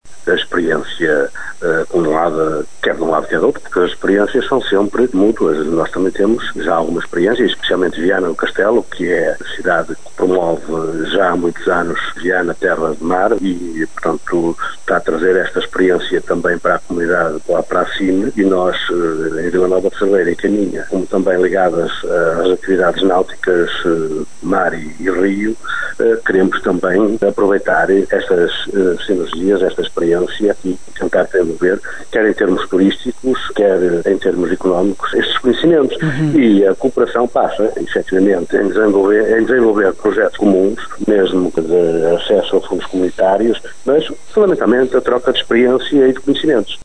Viana do Castelo, Caminha e Vila Nova de Cerveira querem imitar o sucesso gaulês e aplicar o conceito no Alto Minho, como explicou à Rádio Caminha o autarca cerveirense, Fernando Nogueira.